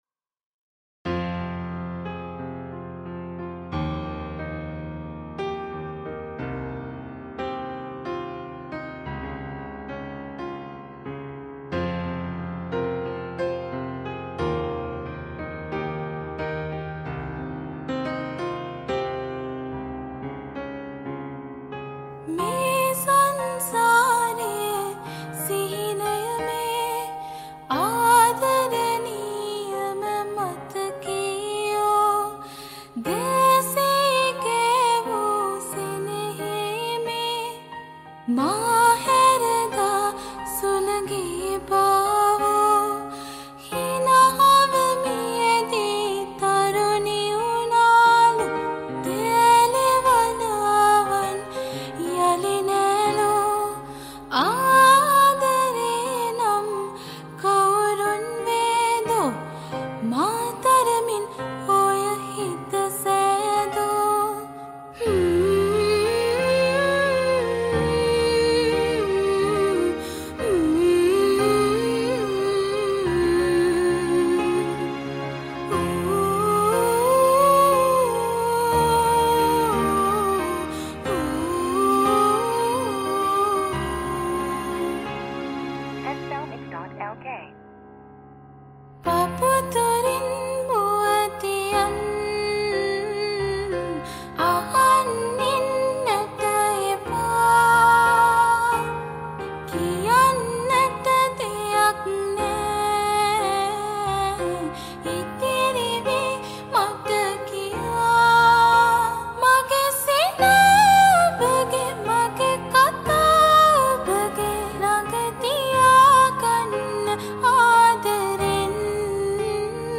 This cover song is sung by a Vocalizer Student Cover Song